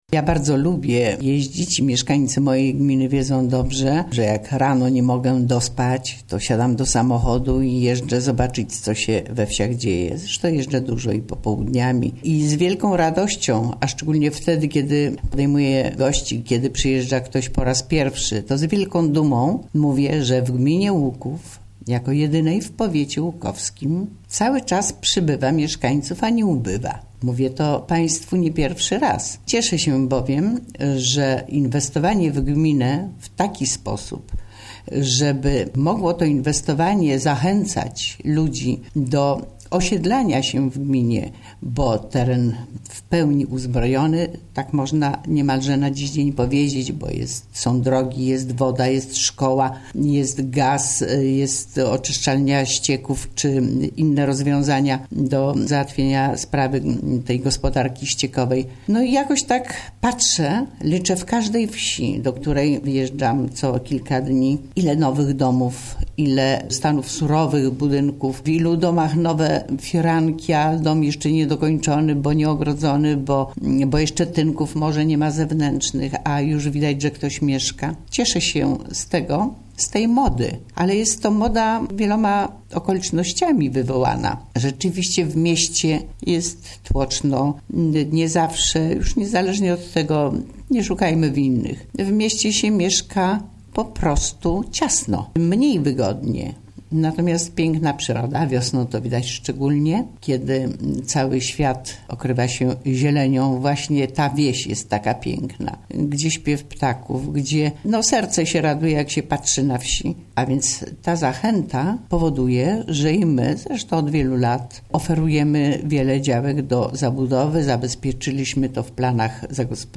Wójt Gminy Łuków
Kazimiera Goławska